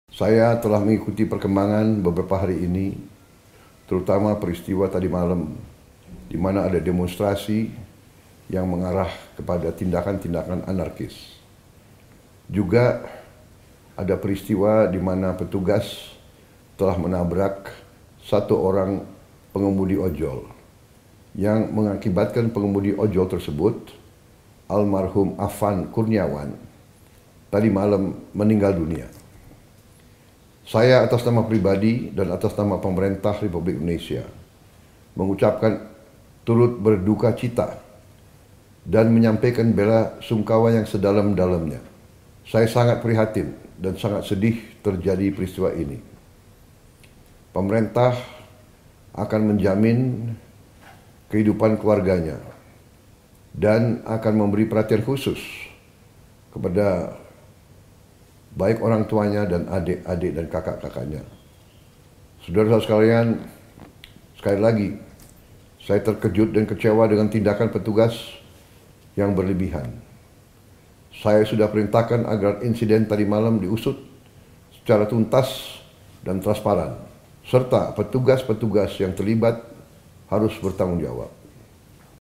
Presiden RI Prabowo Subianto menyampaikan sound effects free download